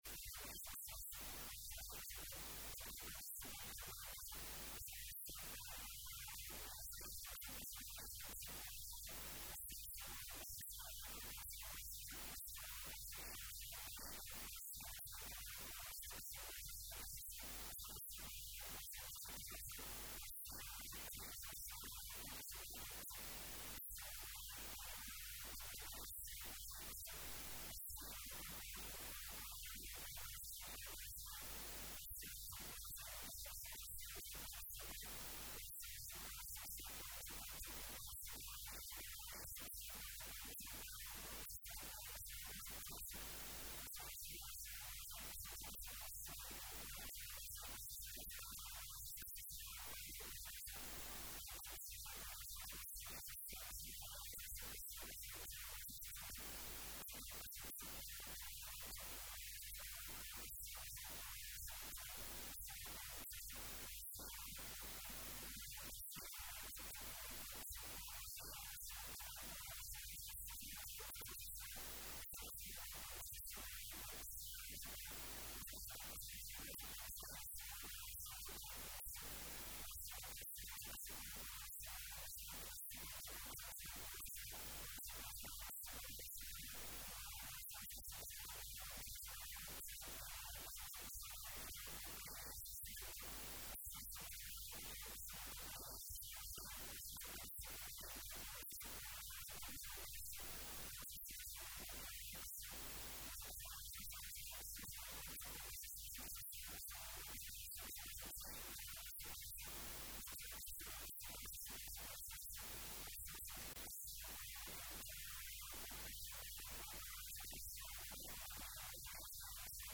Danjire Cali Saciid Fiqi oo la hadlay Laanta Afka Soomaaliga ee VOA oo bahwadaag la ah Radio Muqdisho Codka Jimhuuriyadda Soomaaliya ayaa tilmaamay in Dowladdu xoogga saarayso hanaanka dib usoo celinta muwadiniinta Soomaaliyeed si ay mar kale ola midoobaan waalidiintooda.
Halkaan hoose ka dhageyso Codka Danjiraha: